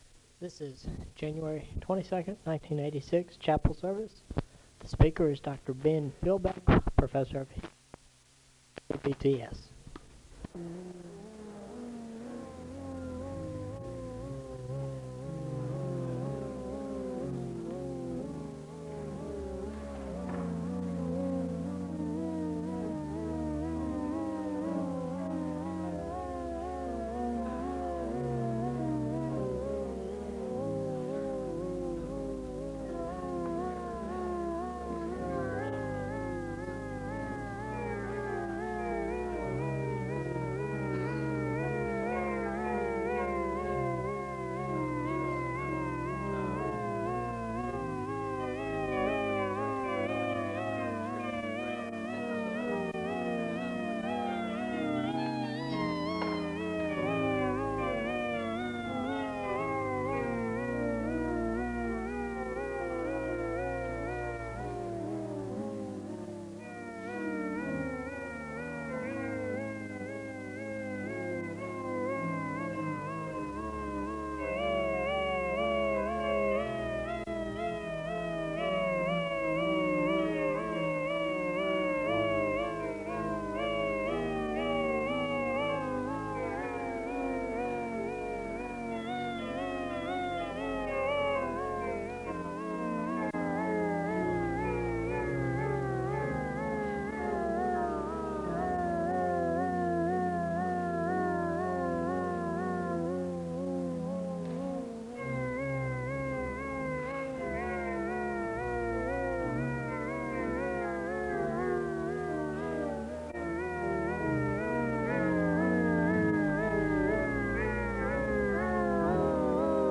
The service begins with organ music (0:00-3:12). The congregation sings a hymn (3:13-6:22). There is a Scripture reading from I Samuel 8 and a moment of prayer (6:23-8:30). The choir sings a song of worship (8:31-11:31).